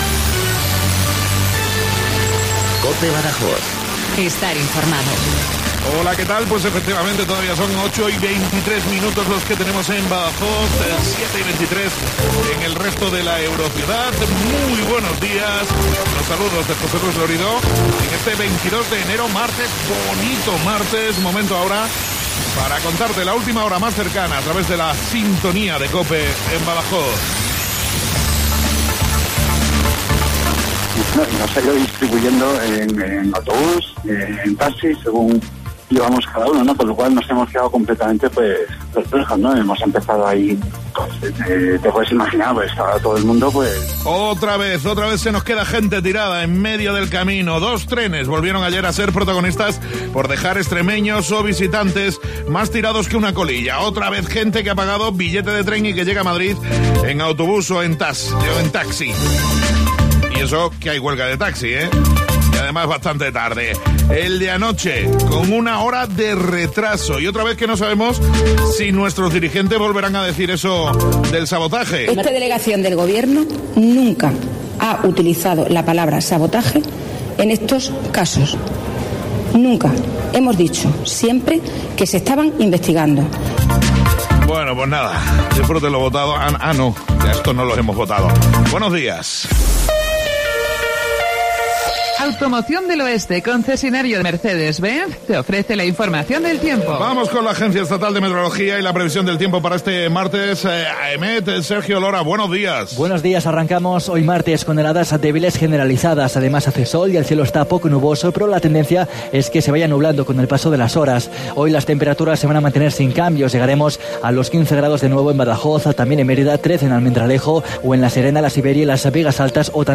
INFORMATIVO LOCAL BADAJOZ 0824